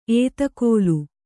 ♪ ētakōlu